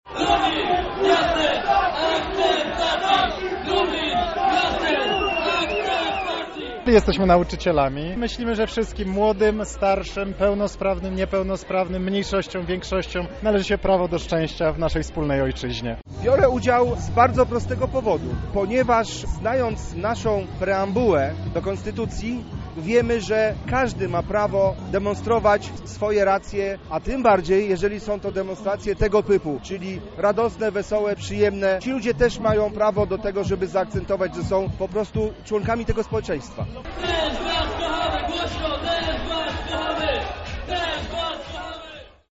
Nasza reporterka zapytała zgromadzonych, dlaczego idą w Marszu Równości.